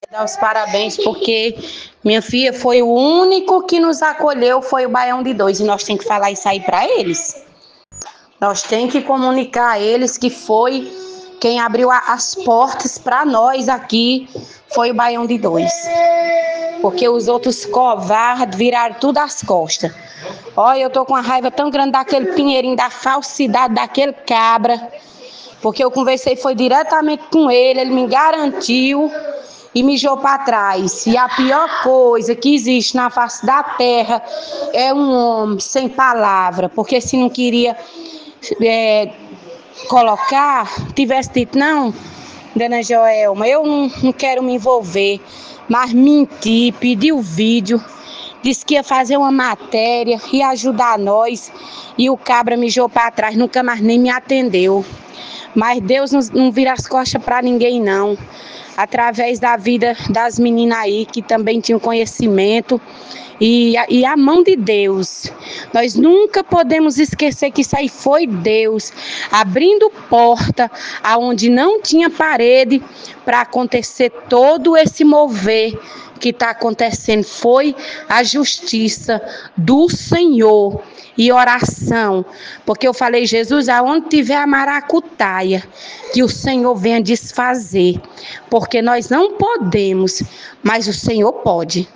Morador fala sobre o problema